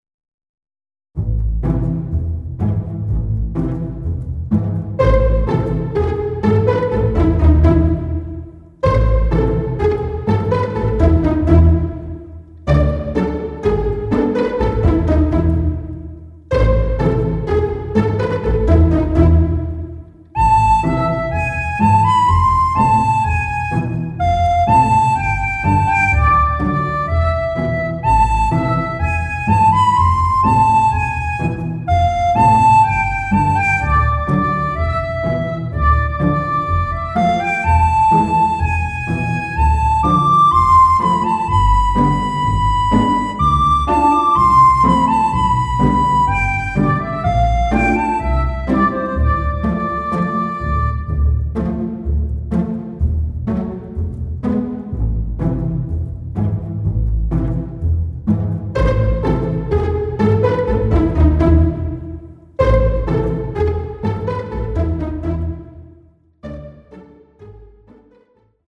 小悪党が何か悪だくみをしてる時に流れそうな怪しい曲。何か作戦会議をしてるシーンにも合うかも